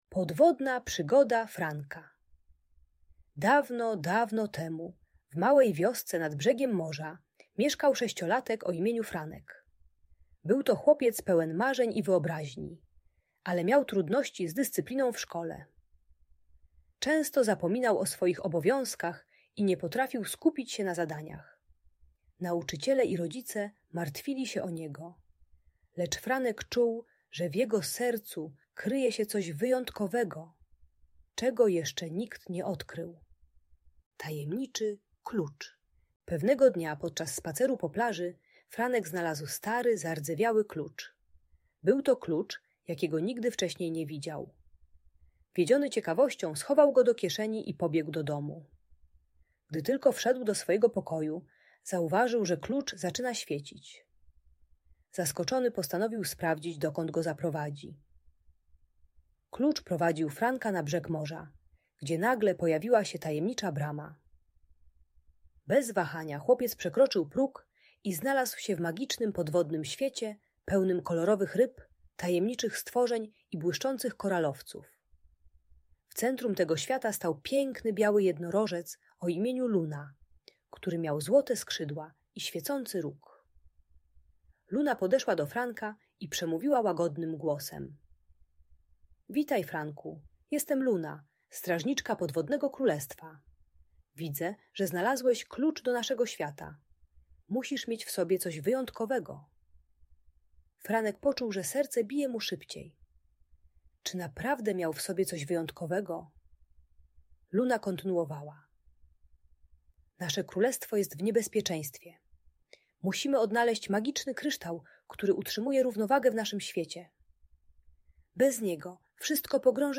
Podwodna Przygoda Franka - Szkoła | Audiobajka